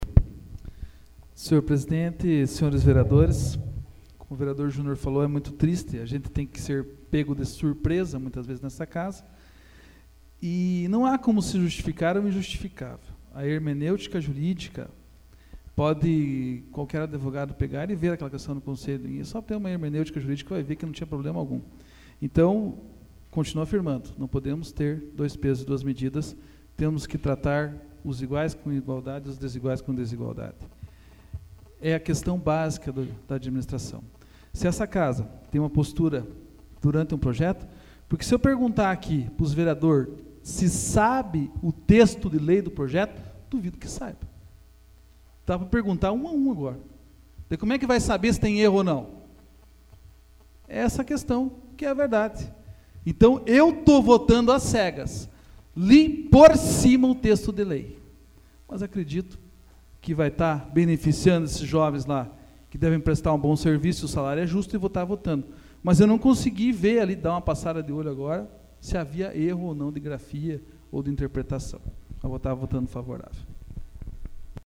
Discussão do Projeto AVULSO 25/03/2014 João Marcos Cuba